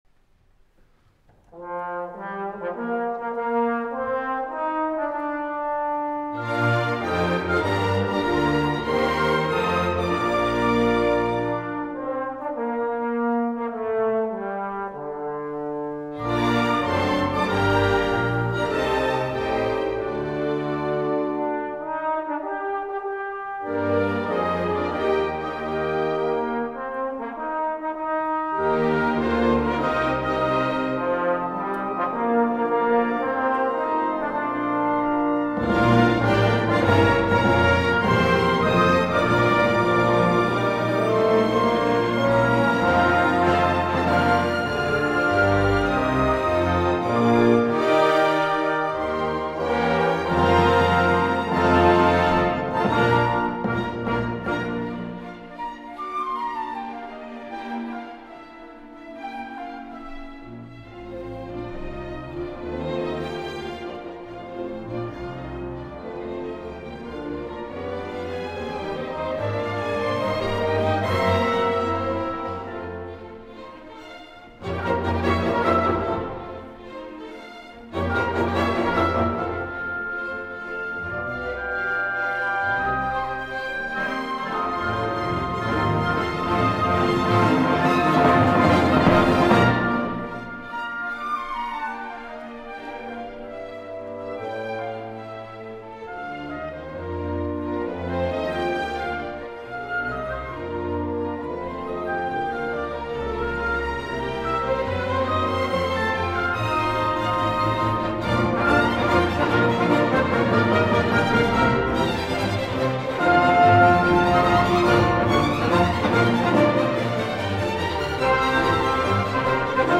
Mendelssohn 2e symfonie, Lobgesang - Live Concert HD.mp3